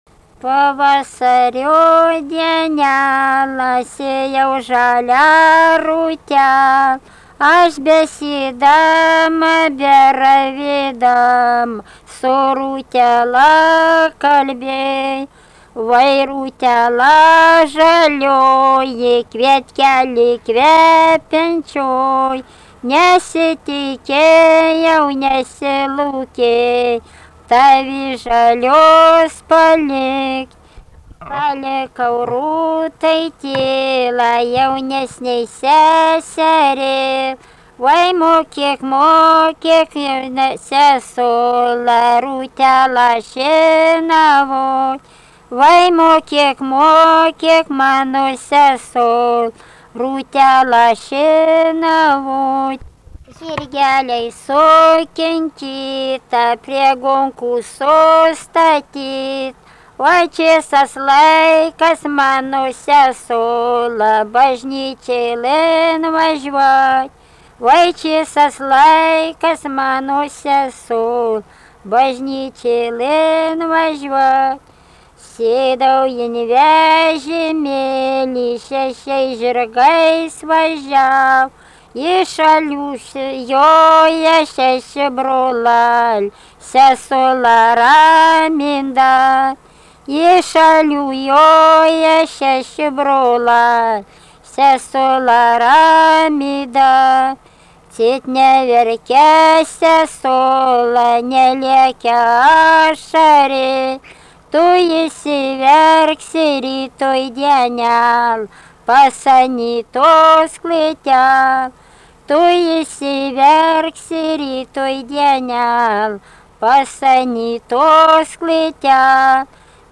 daina, vestuvių